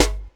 Wanga Gut (Snare 2).wav